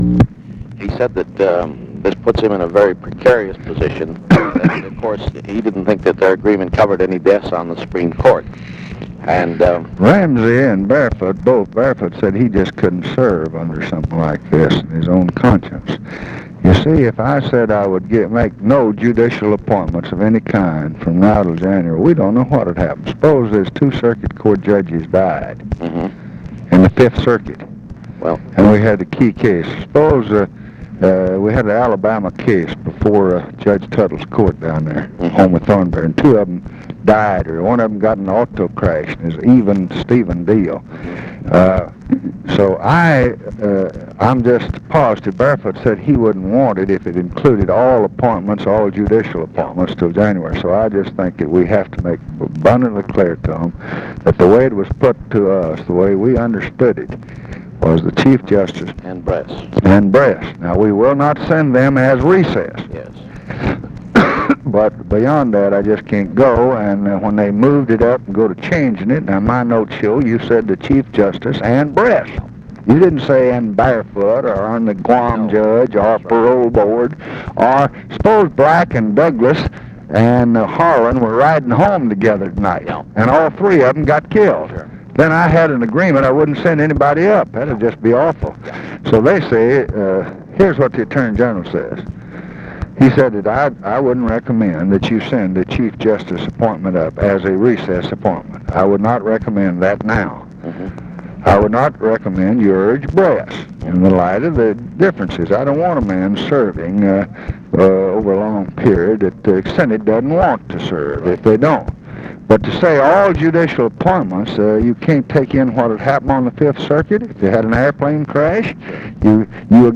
Conversation with MIKE MANATOS, October 11, 1968
Secret White House Tapes